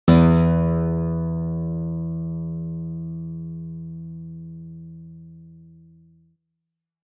The guitar sound is much more complex:
guitar low e
guitar-low-e.mp3